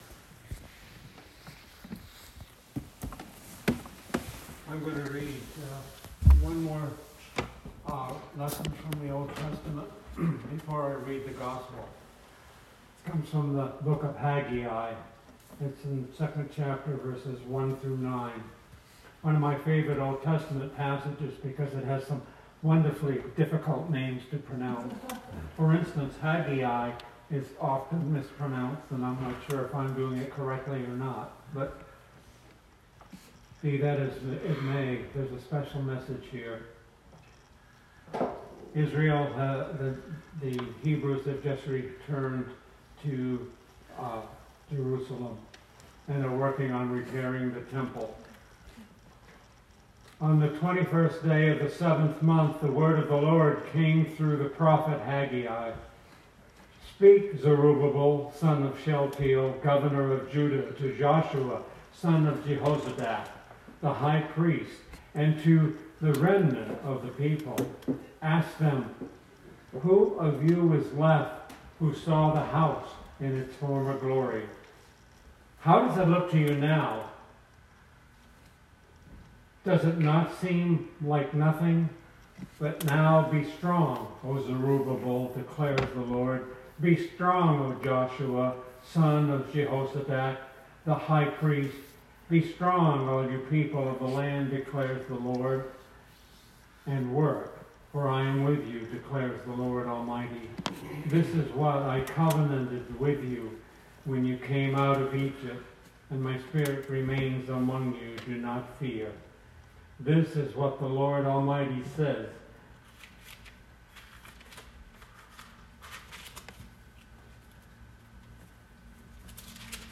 Sermon 2020-02-16